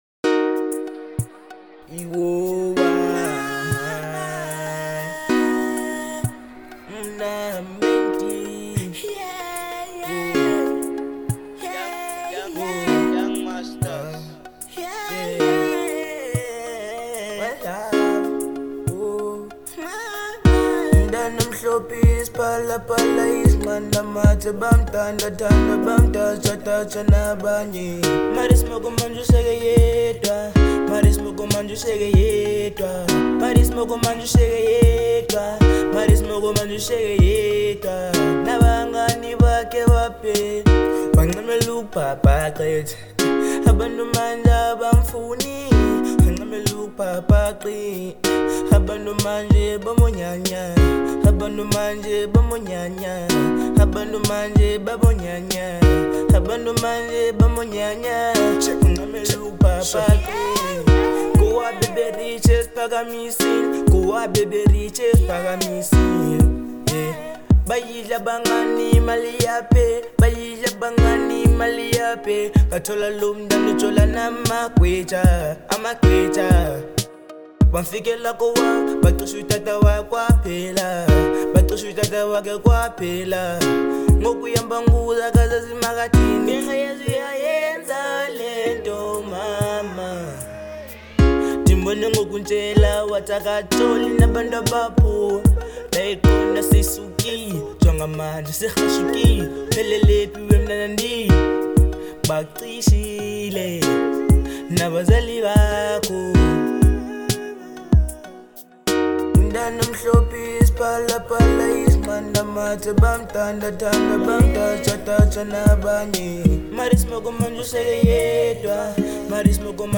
03:12 Genre : Trap Size